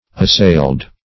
Assailed (-s[=a]ld"); p. pr.